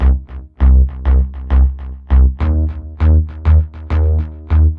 Dance music bass loop - 100bpm 61